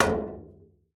sounds / steps / metal_1.ogg
metal_1.ogg